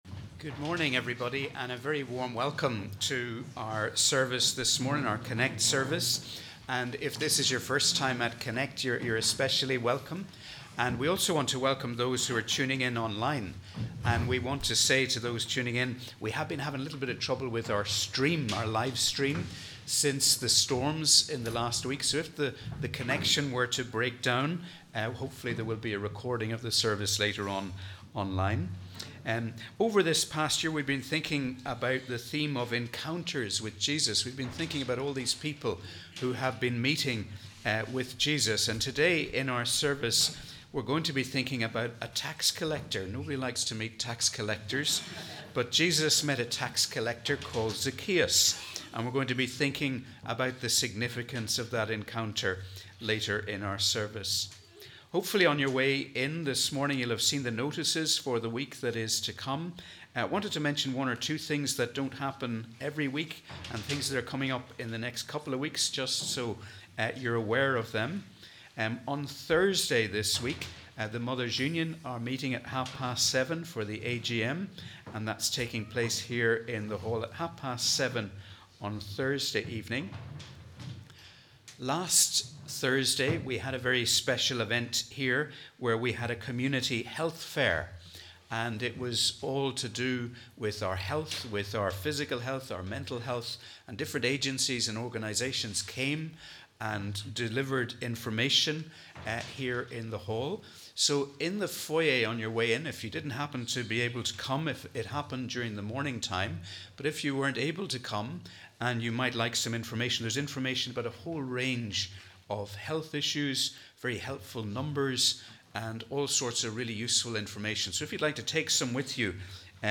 We warmly welcome you to our CONNEC+ service as we worship together on the 4th Sunday after the Epiphany.